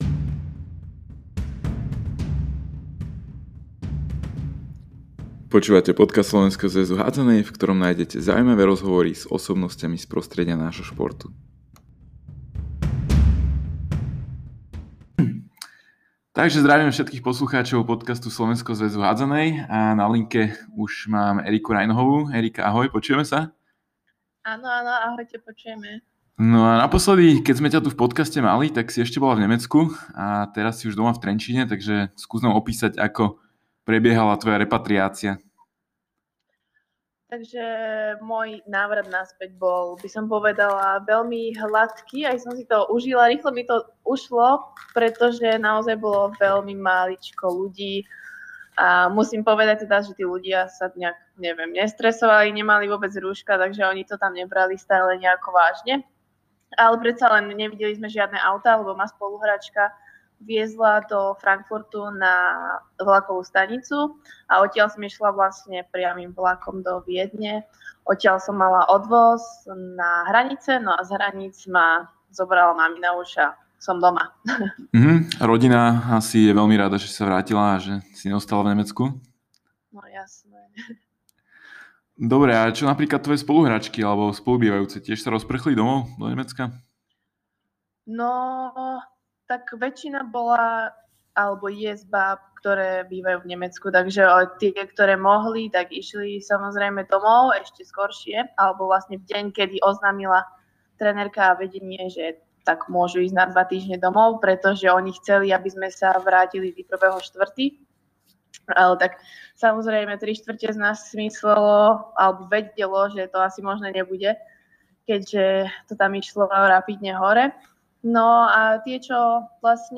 Tie najlepšie audio rozhovory z hádzanej na Slovensku.